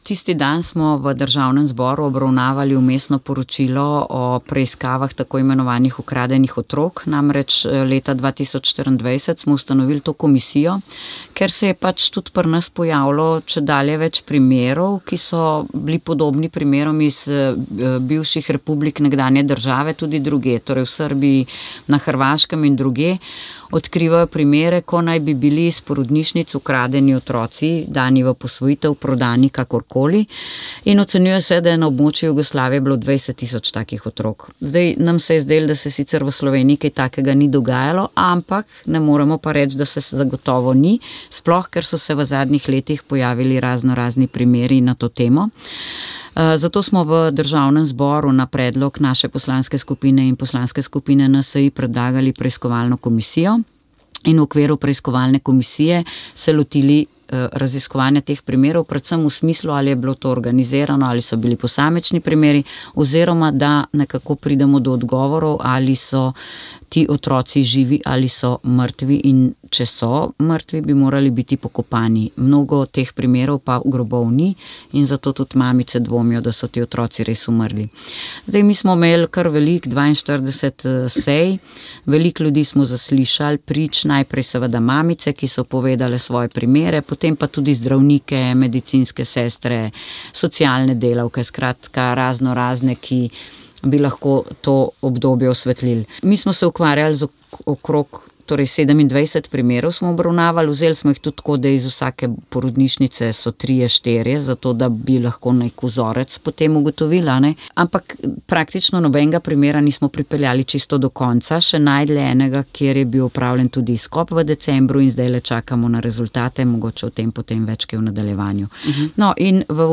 infopogovorpolitikaEvropska unijavolitve